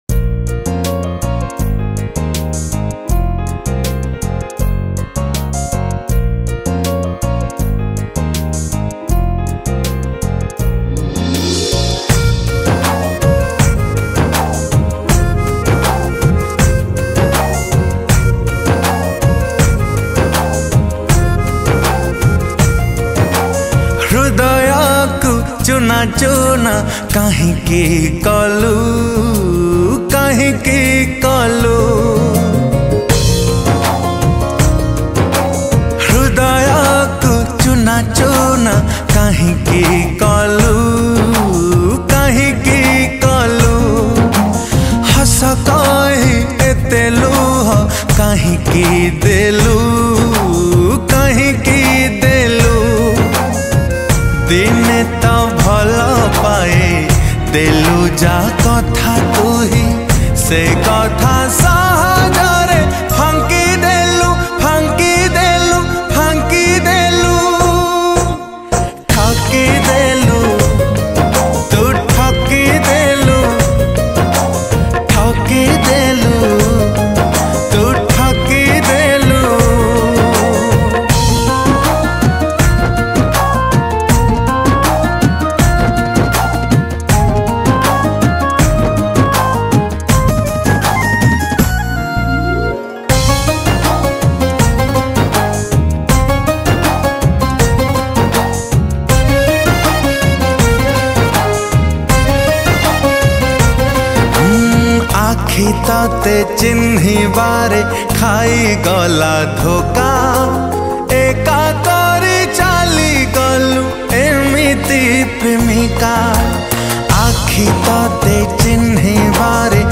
All Odia Sad Romantic Songs